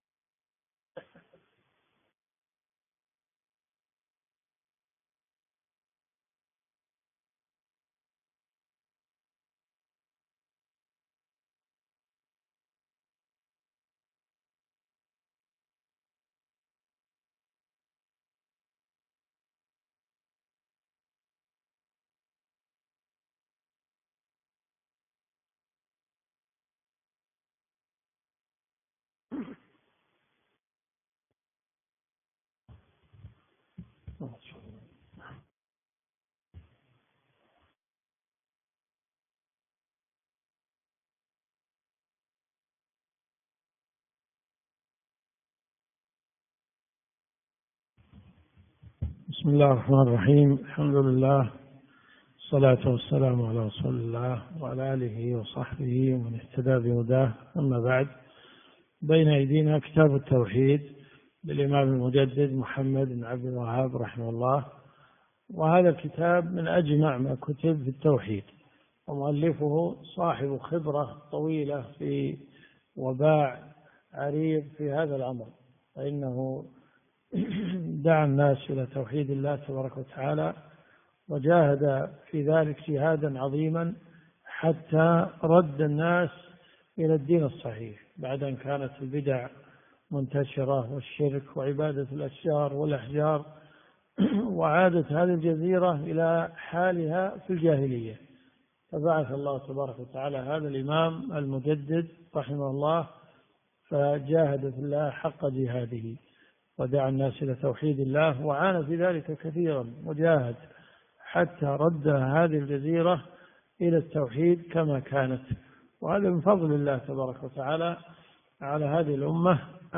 دروس صوتيه ومرئية تقام في جامع الحمدان بالرياض
الدرس في الدقيقة 14.30 . شرح مأخوذ من مكان آخر ليجبر السقط